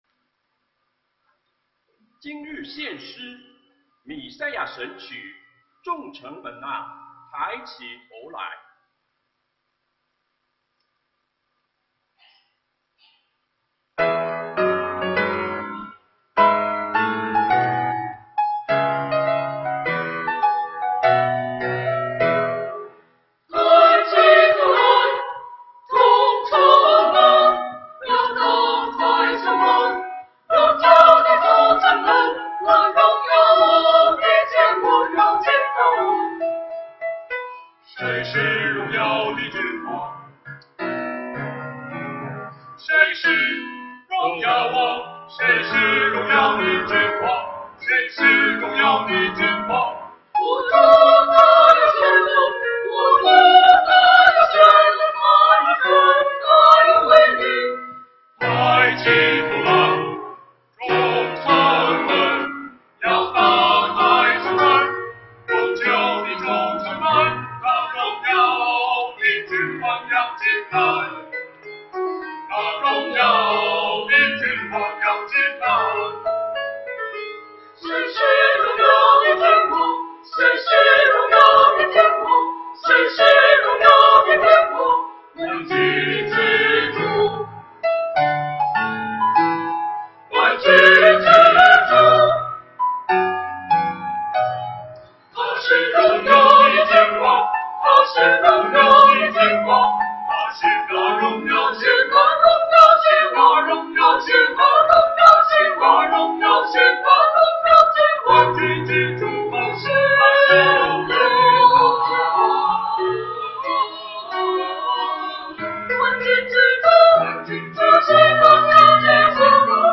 团契名称: 大诗班 新闻分类: 诗班献诗 音频: 下载证道音频 (如果无法下载请右键点击链接选择"另存为") 视频: 下载此视频 (如果无法下载请右键点击链接选择"另存为")